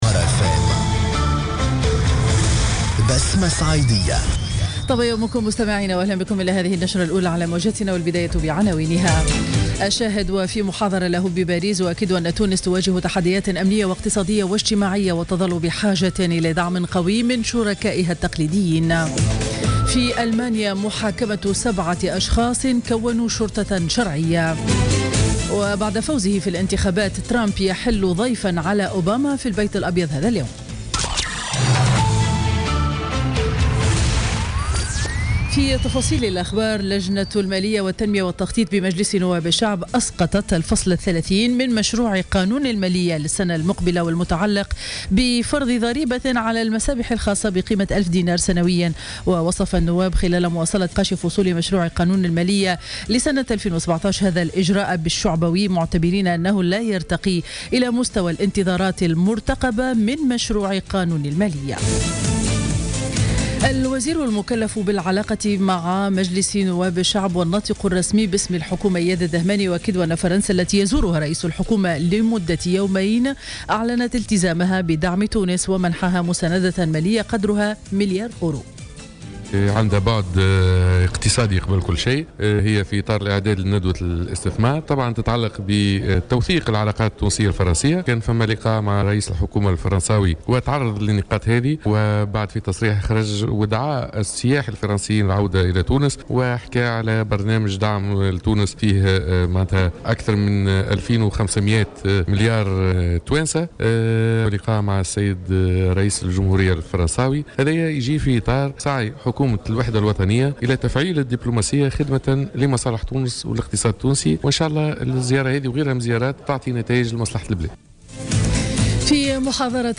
نشرة أخبار السابعة صباحا ليوم الخميس 10 نوفمبر 2016